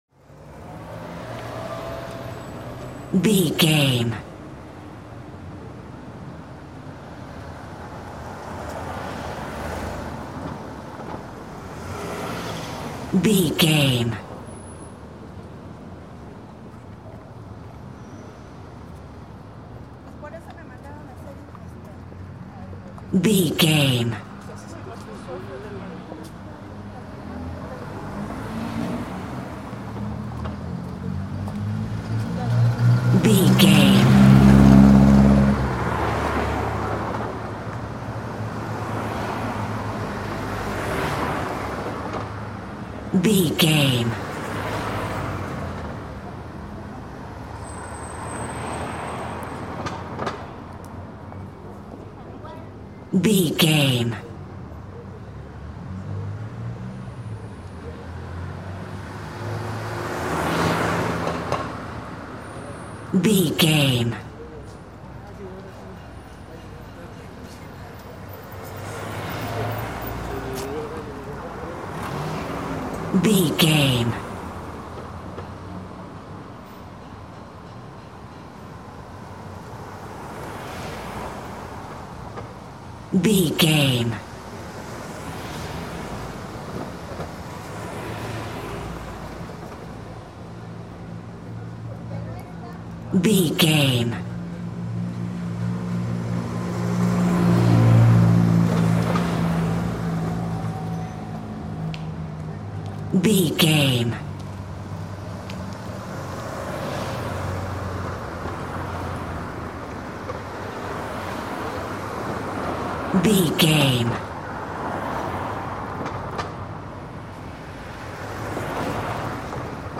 City rumble calm street
Sound Effects
urban
calm
ambience